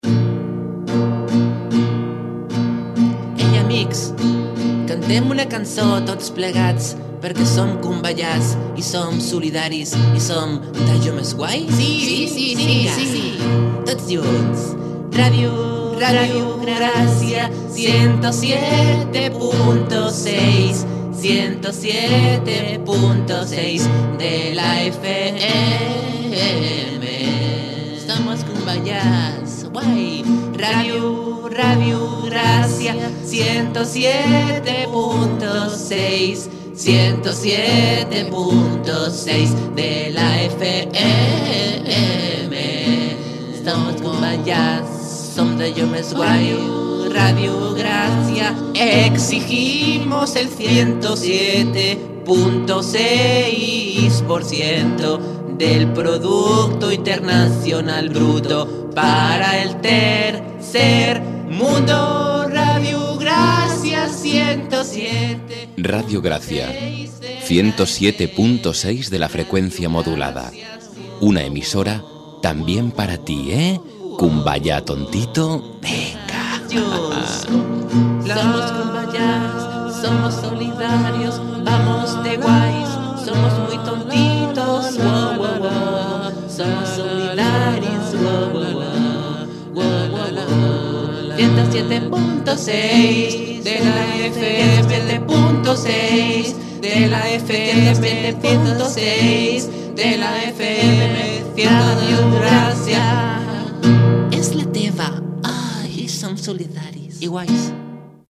Indicatiu de l'emissora i freqüència "kumbayà".